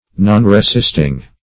Nonresisting \Non`re*sist"ing\, a. Not making resistance.
nonresisting.mp3